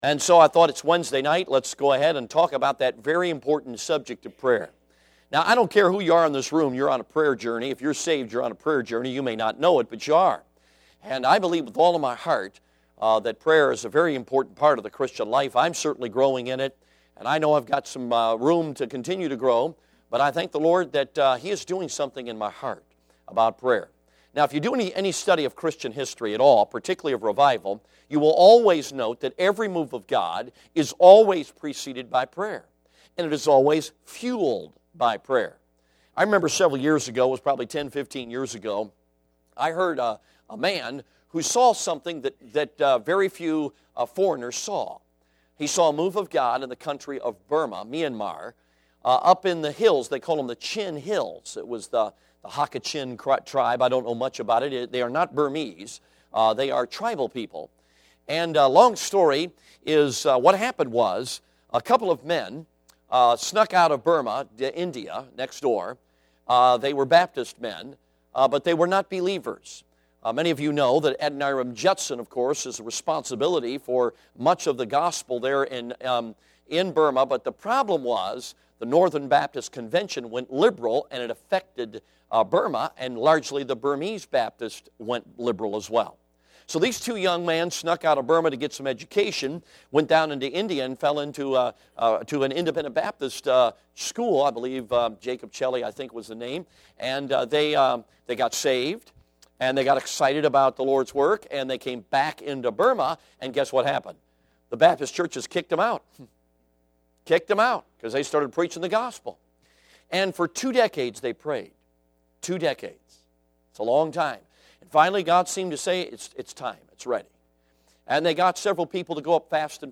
Service Type: Midweek Service